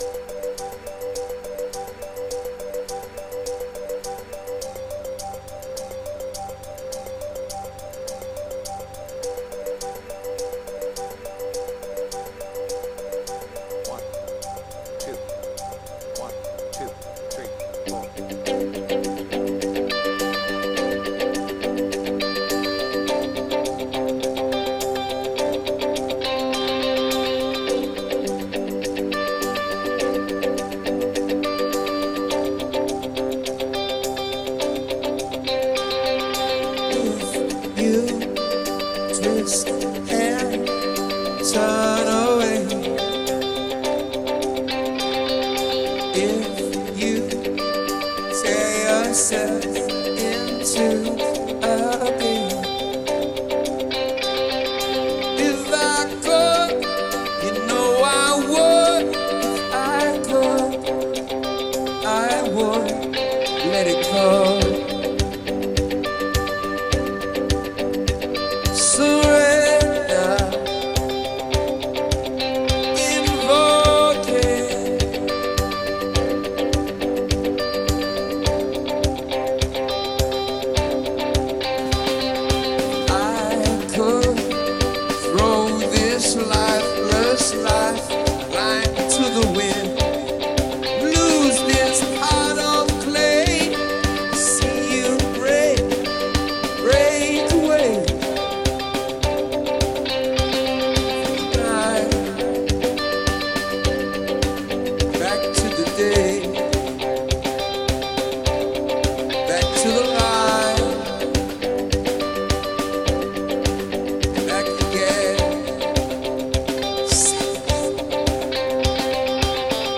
BPM : 104
Tuning : D
Without vocals
Mashup of different live version